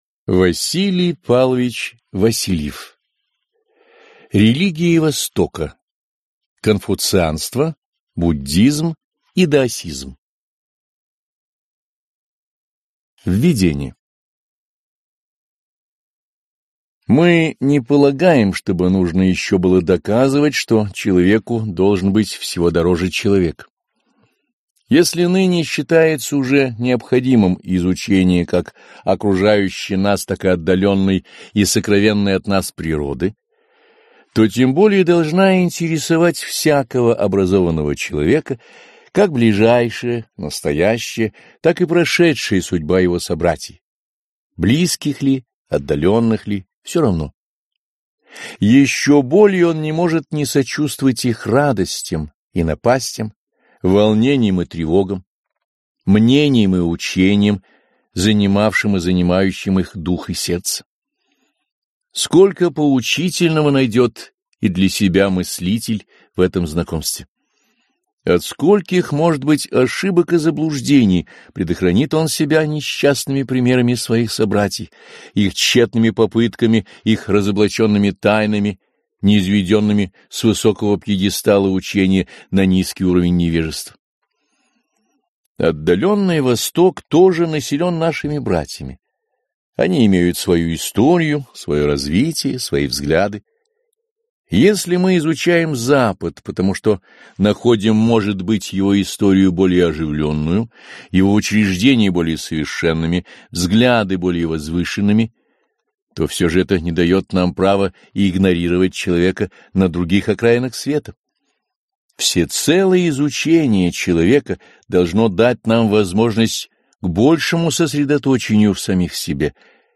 Aудиокнига Религии Востока. Конфуцианство, буддизм и даосизм